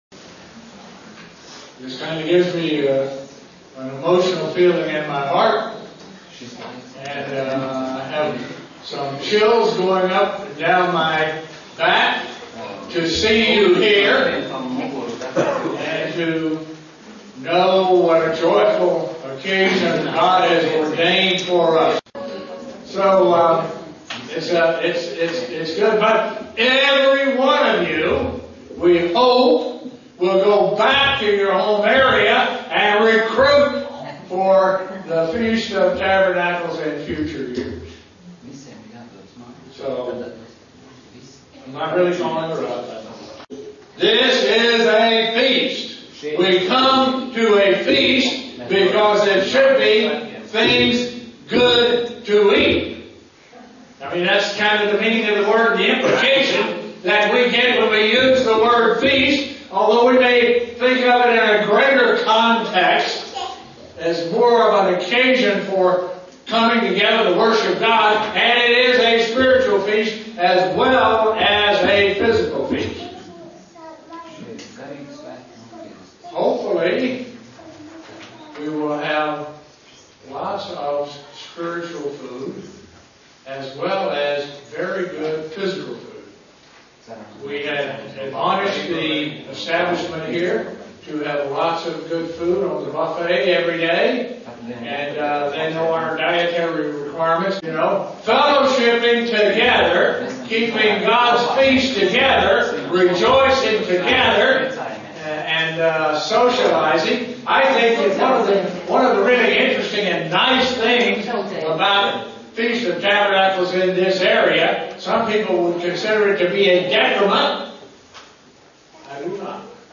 Opening to the Feast of Tabernacles in Estonia SEE VIDEO BELOW
Print Opening to the Feast of Tabernacles in Estonia SEE VIDEO BELOW UCG Sermon Studying the bible?